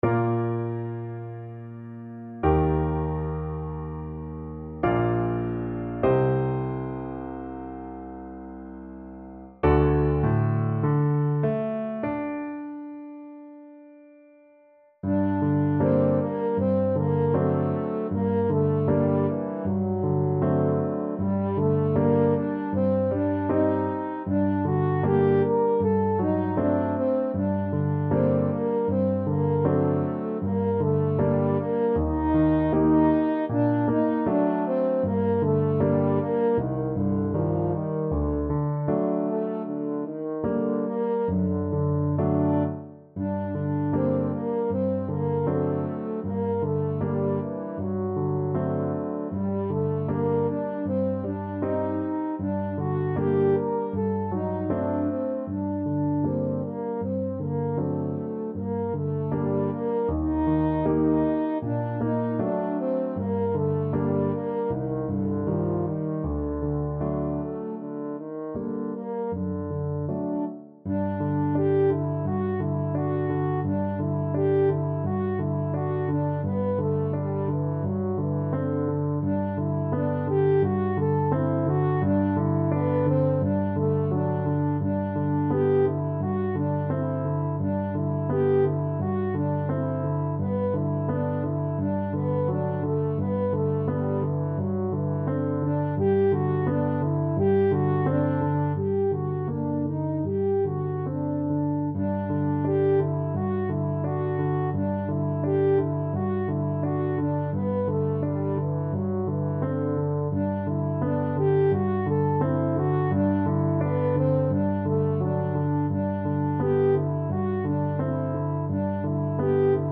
French Horn
Bb major (Sounding Pitch) F major (French Horn in F) (View more Bb major Music for French Horn )
4/4 (View more 4/4 Music)
Largo
Classical (View more Classical French Horn Music)